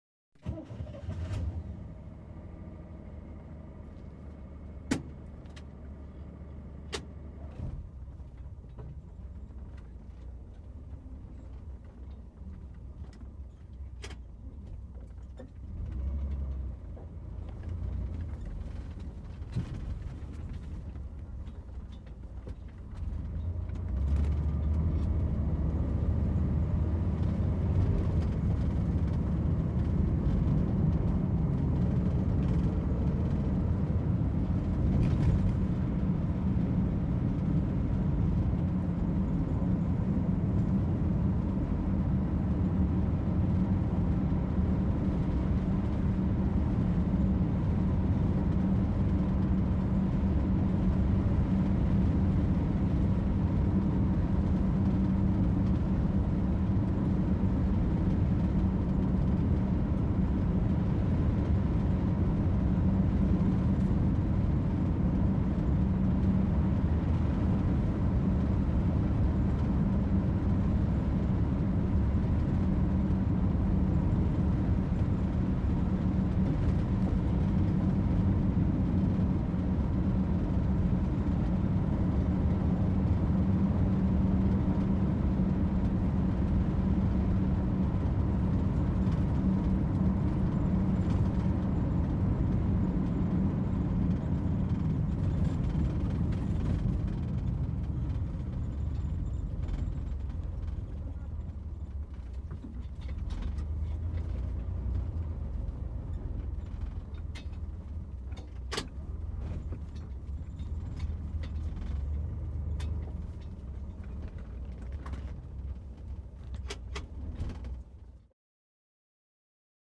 Car Interior - Start Up, Release Brake, Steady Speed